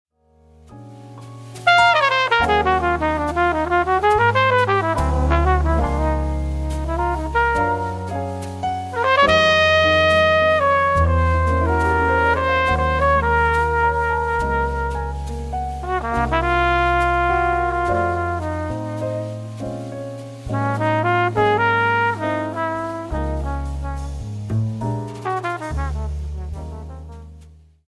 All FAT HEAD SAMPLES ARE RECORDED WITH A  STOCK TRANSFORMER
FAT HEAD Horn Samples
Flugelhorn:
FAT_HEAD_Flugelhorn.mp3